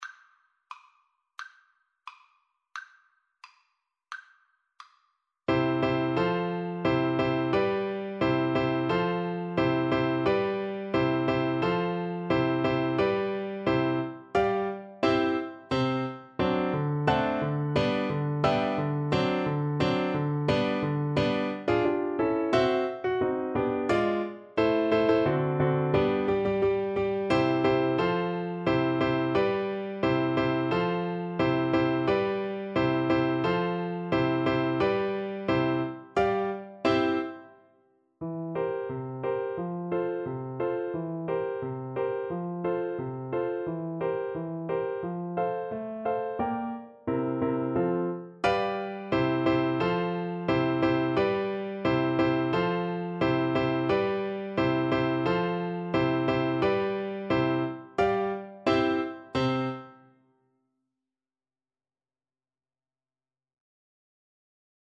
C major (Sounding Pitch) G major (French Horn in F) (View more C major Music for French Horn )
2/4 (View more 2/4 Music)
~ = 88 Stately =c.88
Classical (View more Classical French Horn Music)